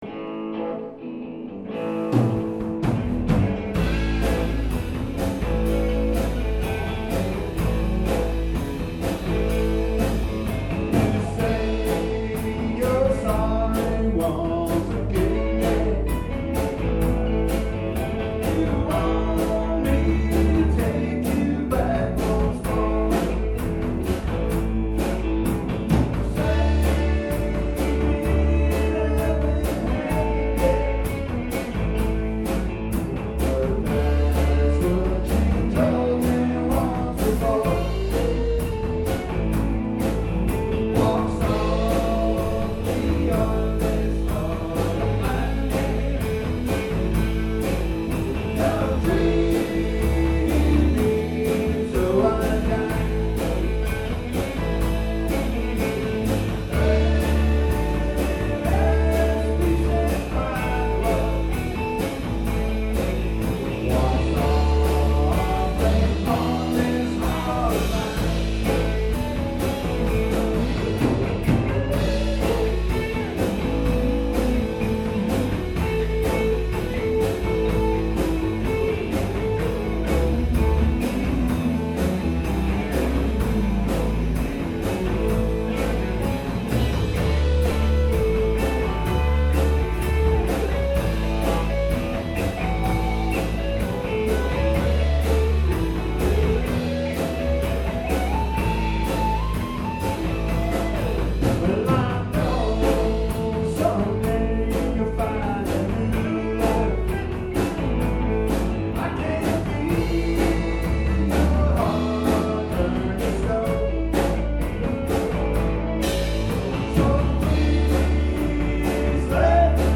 Bad Motor Scooter Band performs at Jollie's Lounge, April 11, 2014